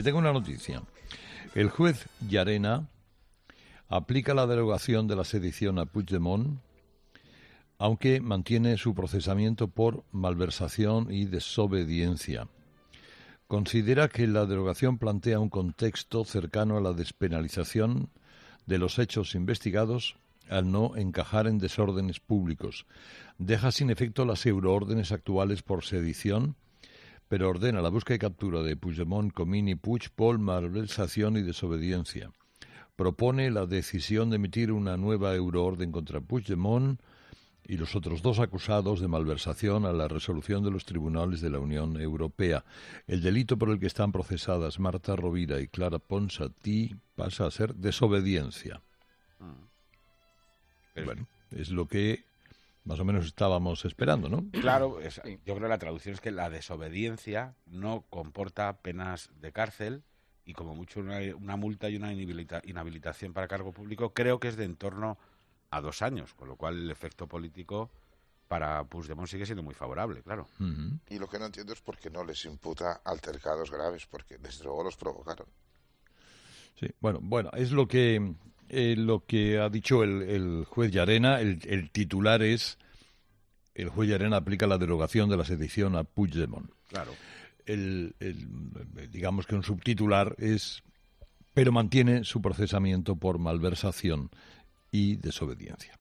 Herrera da la noticia de la decisión del juez Llarena sobre Puigdemont